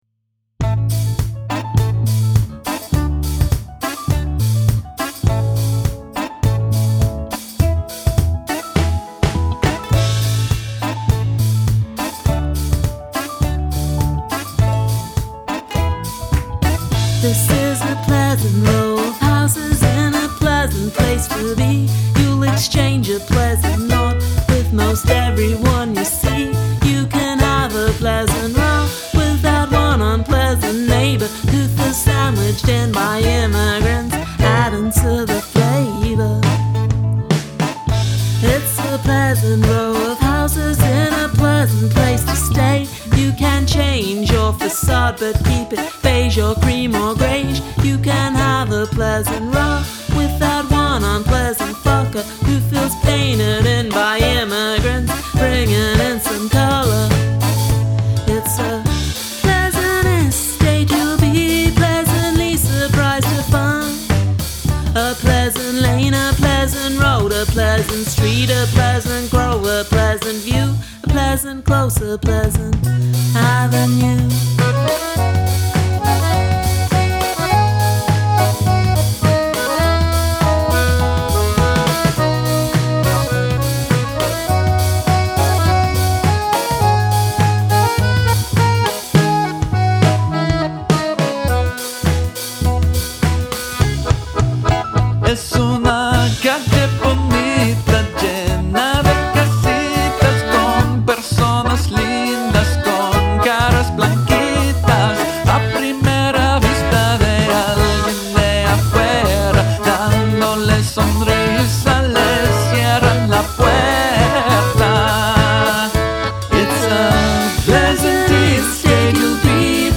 Must include a guest singing or speaking in another language
accordion
Cool intro.